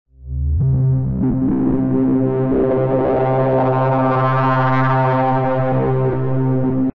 再生するとシンセの音がずっと鳴り続ける状態にします。
♪とりあえずシンセが鳴り続ける状態の音♪(mp3)